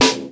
Boom-Bap Snare 87.wav